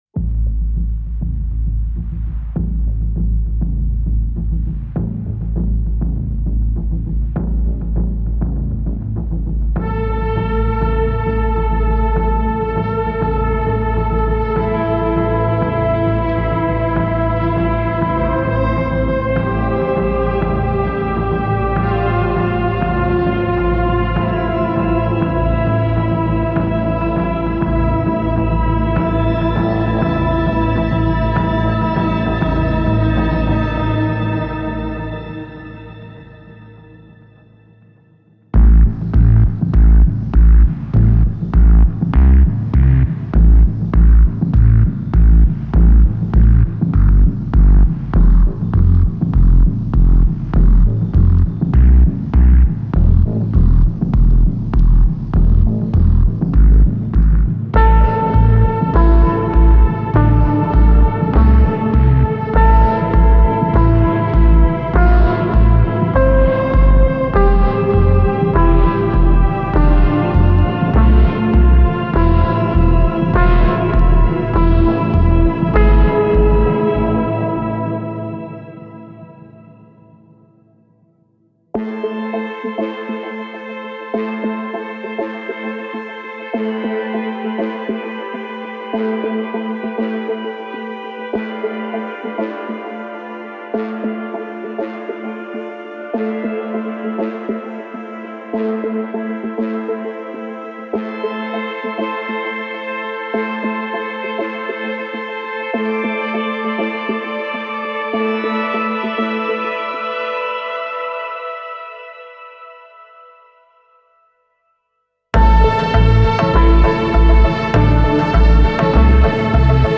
An ominous rumble swells from deep within.